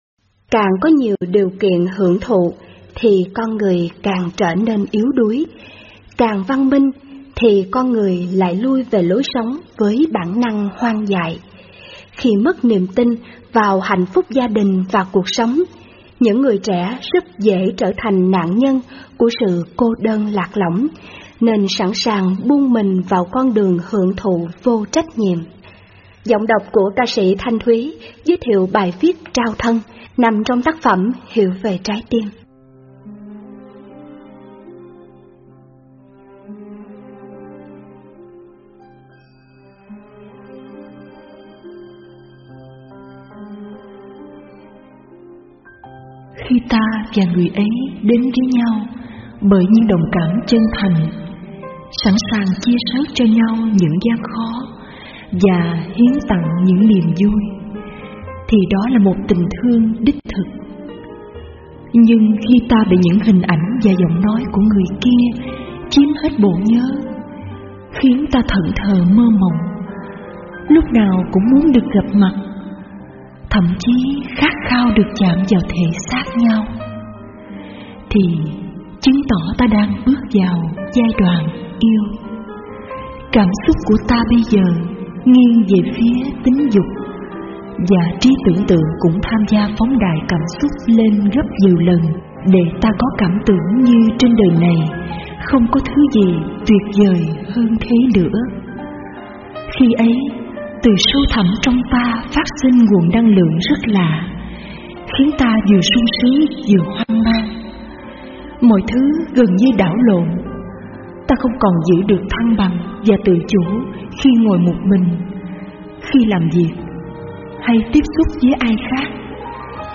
Nghe Mp3 thuyết pháp Hiểu Về Trái Tim (Phần 13: Trao Thân) - ĐĐ. Thích Minh Niệm